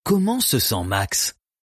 Dog - Living Room - Dog Language [Quiz] | Purina FR
dog_languages_quiz_question1_fr_1.mp3